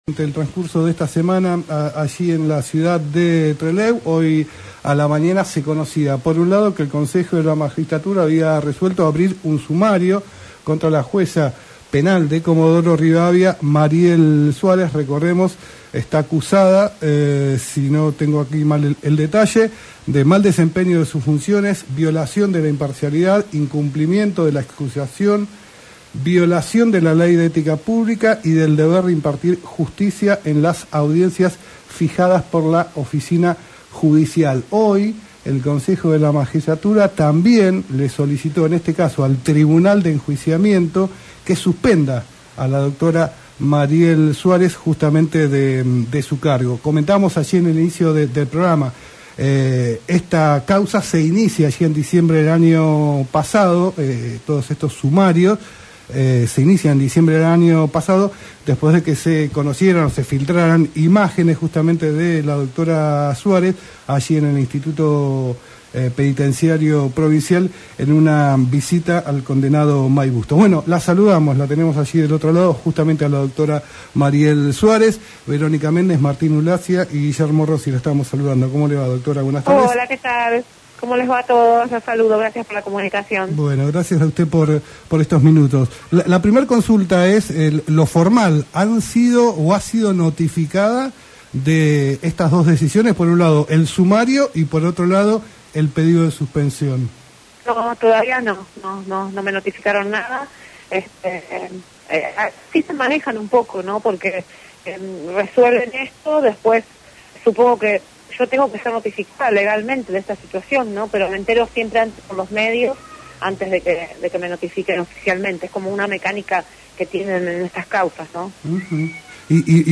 La jueza penal Mariel Suárez, habló en Tarde Para Miles por LaCienPuntoUno sobre el sumario que abrió en su contra el Consejo de la Magistratura para evaluar el pedido para que sea destituida de su cargo.